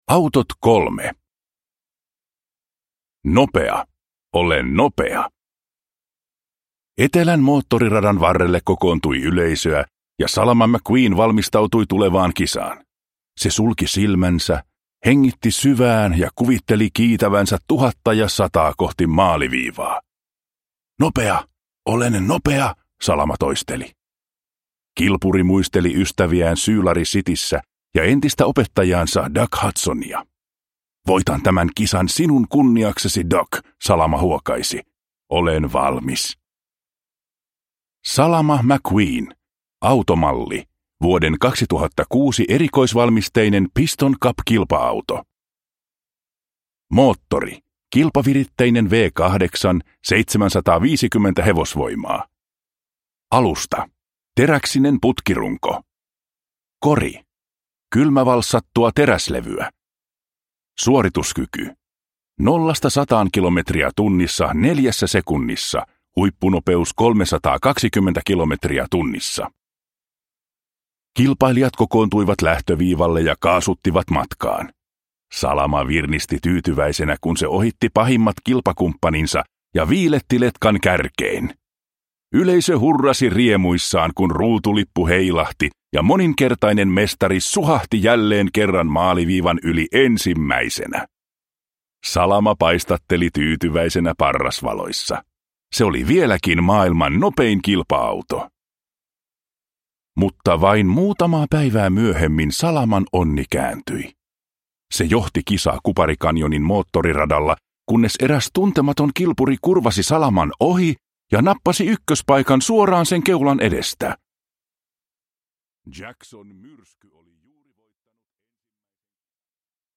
Autot 3 – Ljudbok – Laddas ner